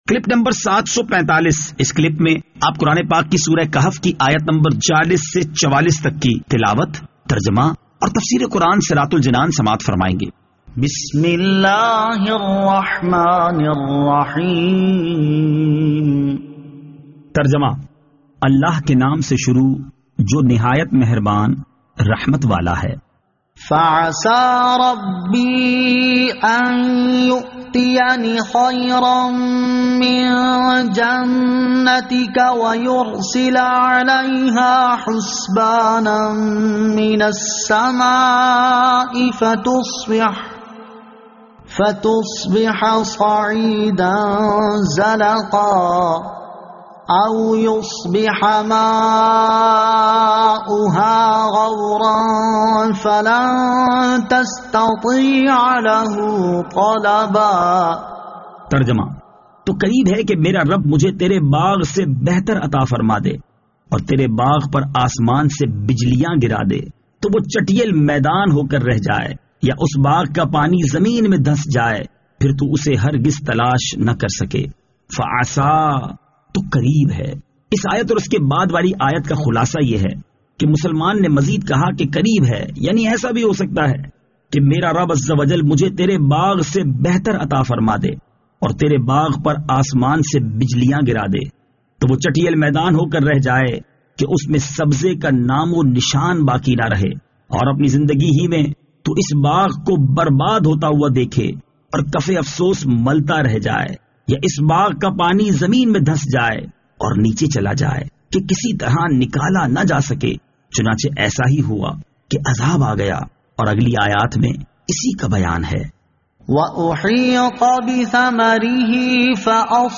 Surah Al-Kahf Ayat 40 To 44 Tilawat , Tarjama , Tafseer